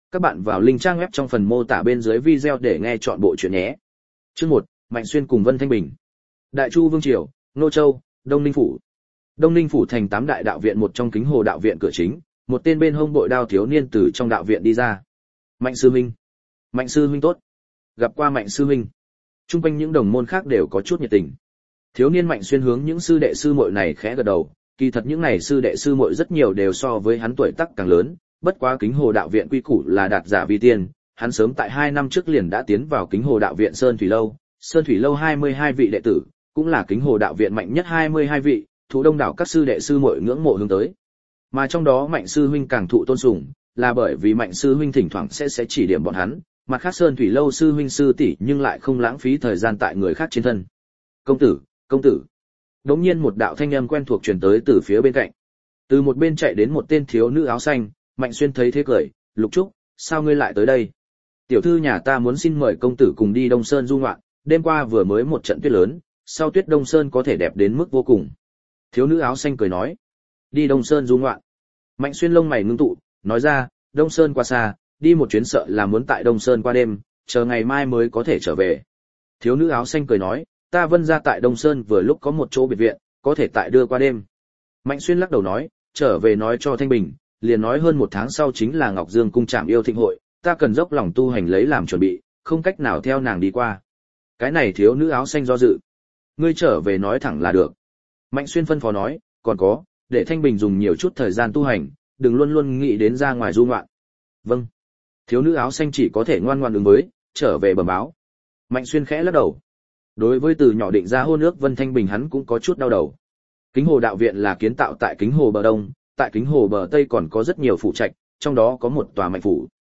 Thương Nguyên Đồ Audio - Nghe đọc Truyện Audio Online Hay Trên TH AUDIO TRUYỆN FULL